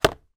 Звуки освежителя воздуха